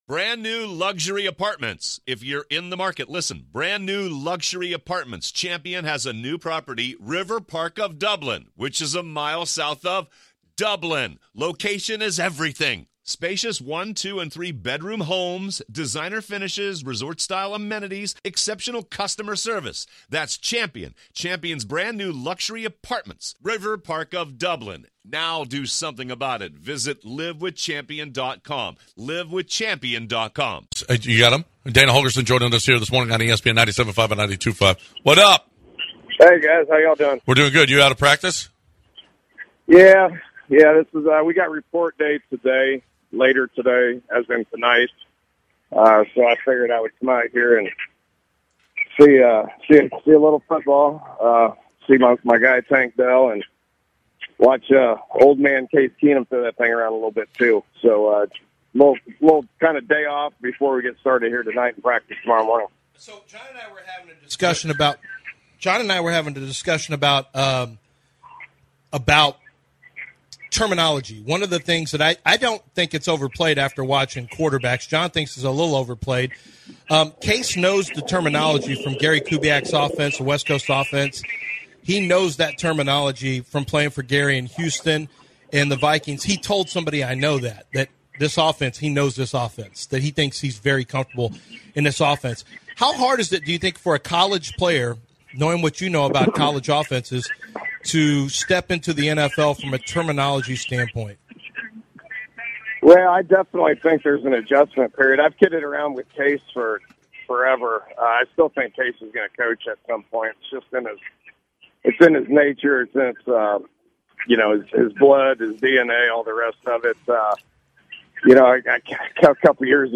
UH Head Coach Dana Holgorsem Interview 8-1-23